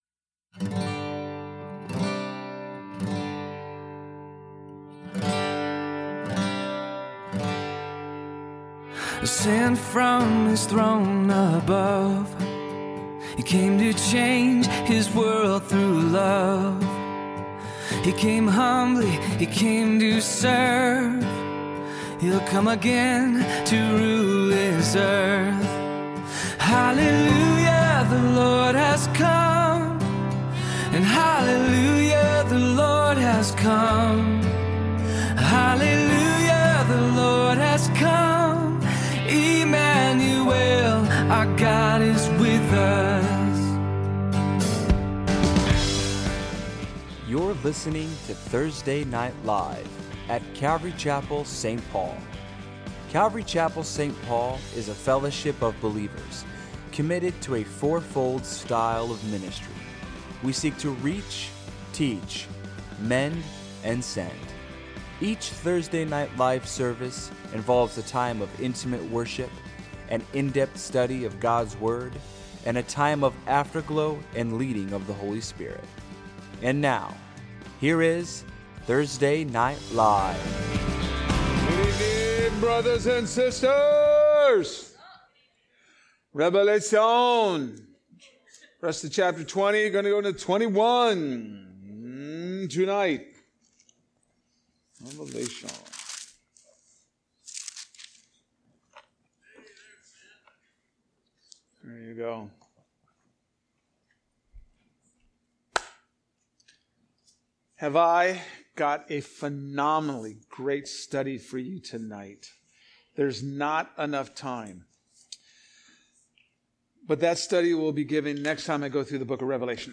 A message from the series "3000 Series."